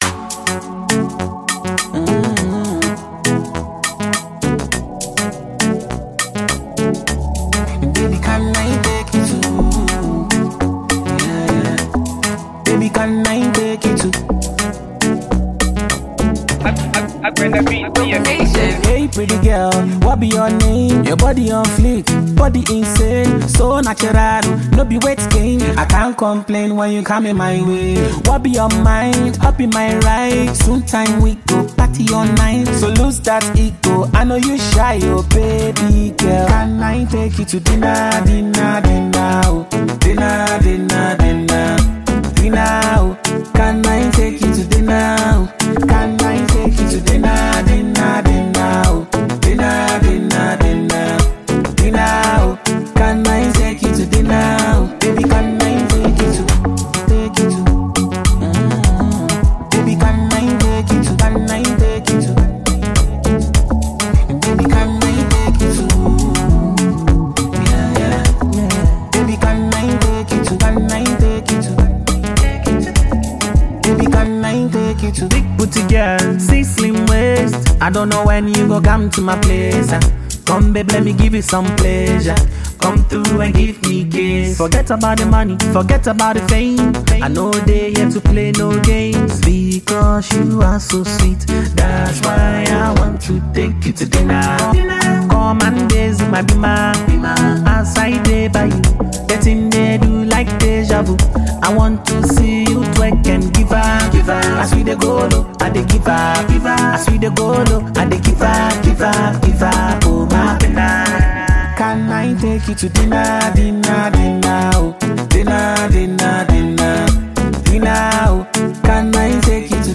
Award-winning Ghanaian musical duo